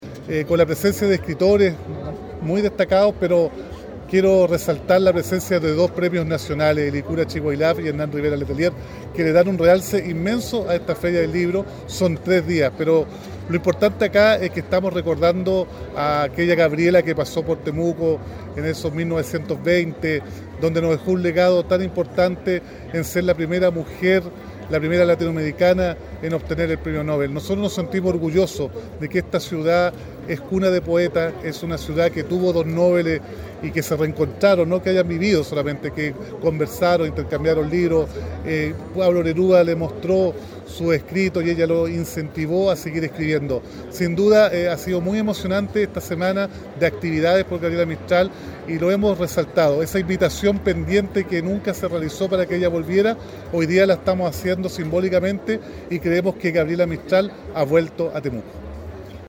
alcalde-de-Temuco-Roberto-Neira.mp3